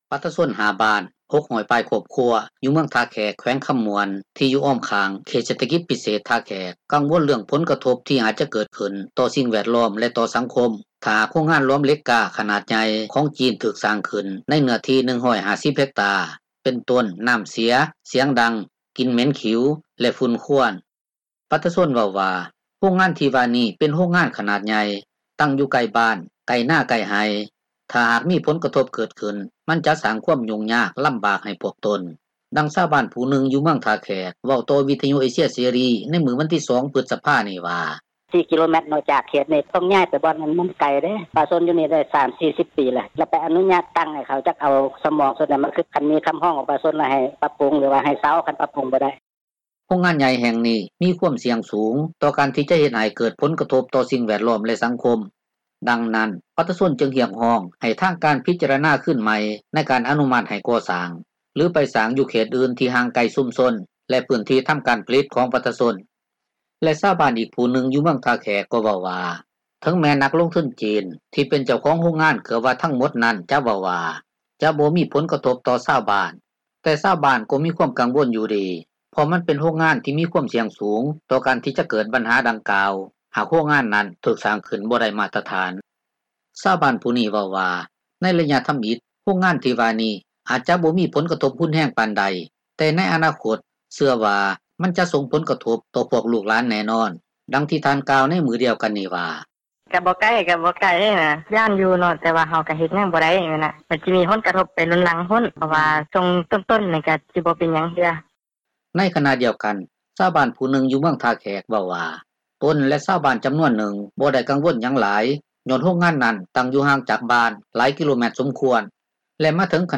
ດັ່ງຊາວບ້ານຜູ້ນຶ່ງ ຢູ່ເມືອງທ່າແຂກ ເວົ້າຕໍ່ວິທຍຸເອເຊັຽເສຣີໃນມື້ວັນທີ 2 ພຶສພານີ້ວ່າ: